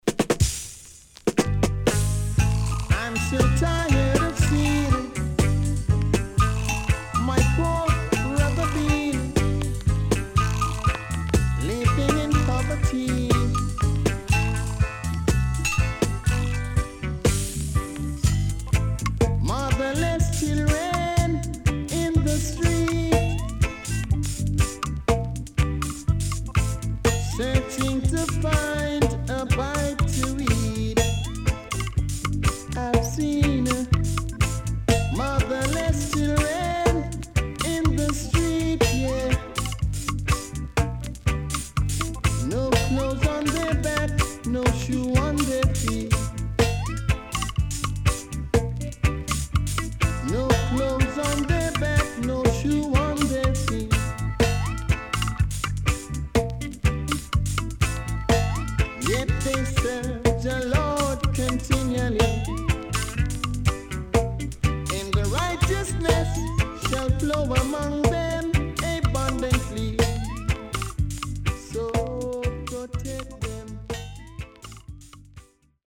SIDE A:全体的にチリノイズ、プチノイズ入ります。